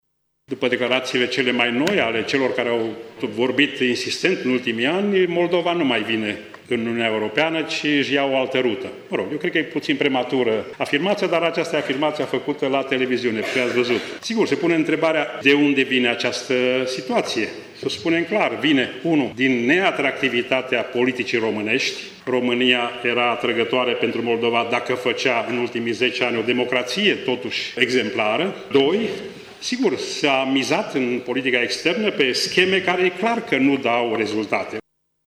Declaraţia aparţine fostului ministru de externe Andrei Marga, care a susţinut, astăzi, o conferinţă la Universitatea „Petru Maior” din Tîrgu-Mureş.